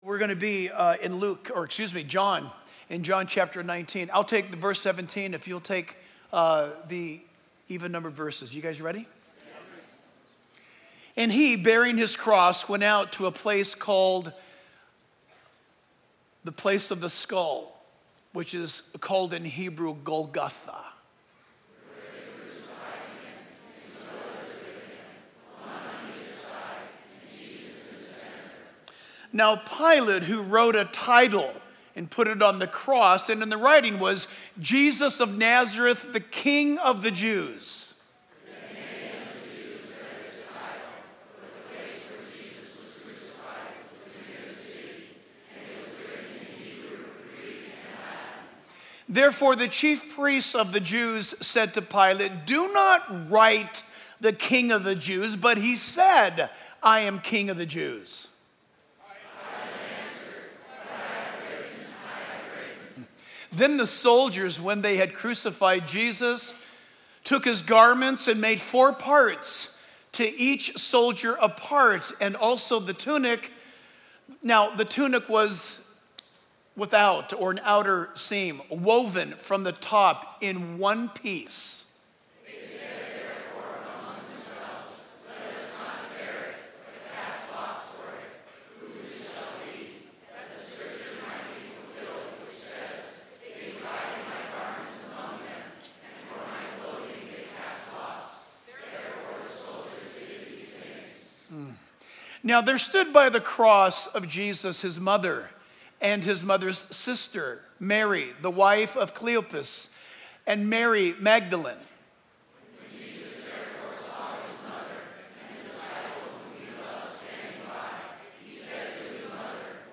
Reference: John 19:17-42 Download Sermon MP3 Download Sermon Notes